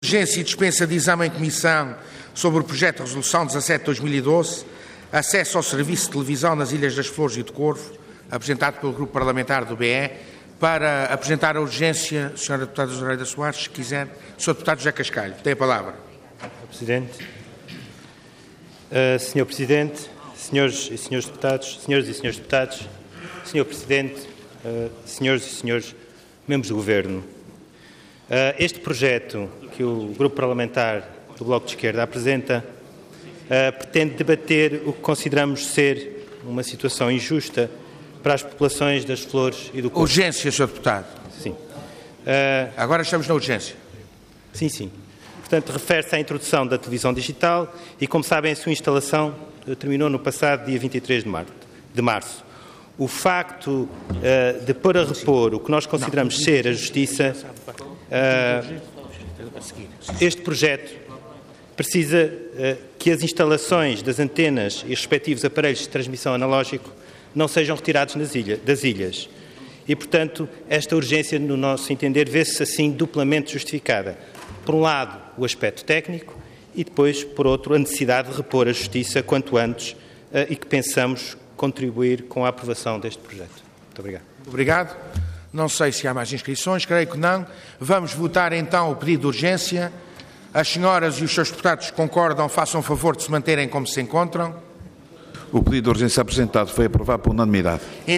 Intervenção Projeto de Resolução Orador José Cascalho Cargo Deputado Entidade BE